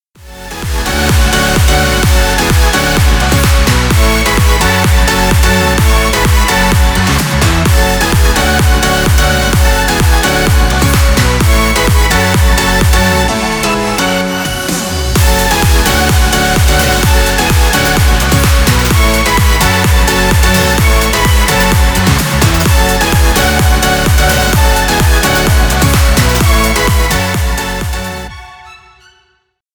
Танцевальные
клубные # без слов